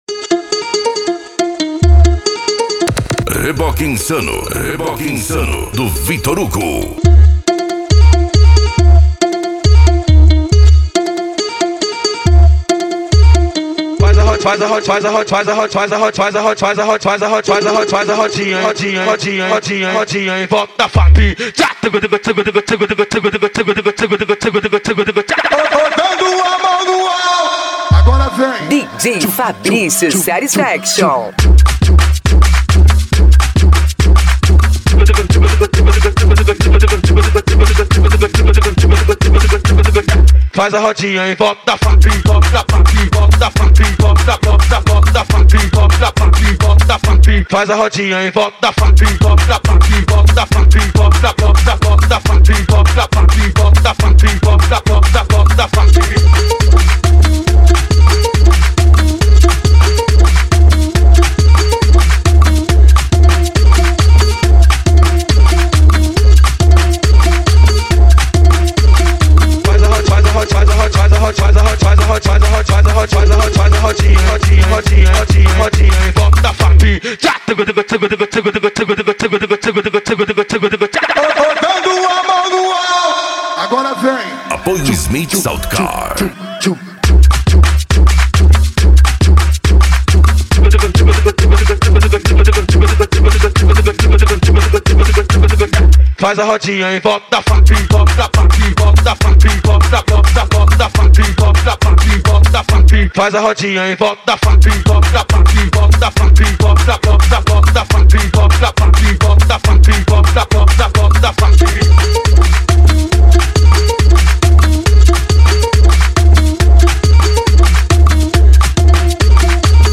Bass
Funk